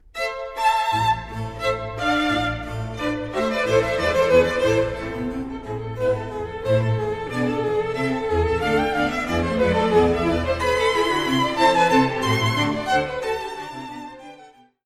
Tónica: La J. S. Bach; Concerto violín la m 1º mov.